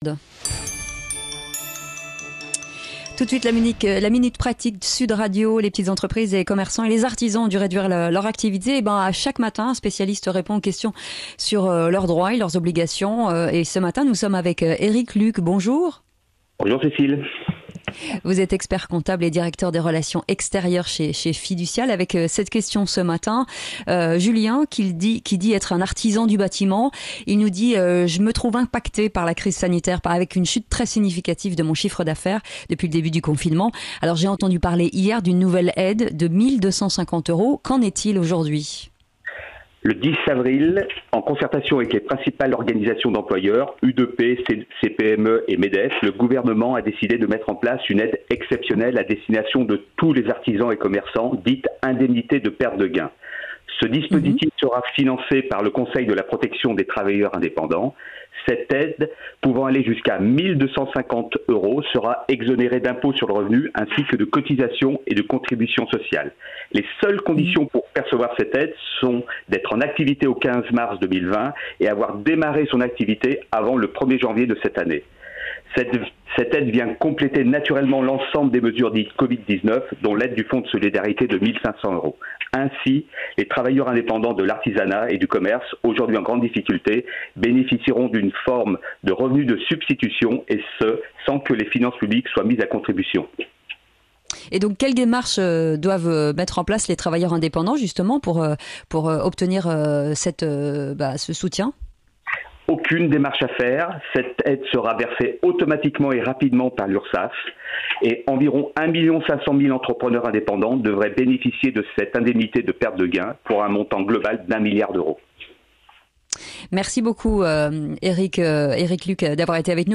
La minute pratique - A 9 heures, chaque jour dans le Grand Matin Sud Radio, des spécialistes Fiducial vous répondent.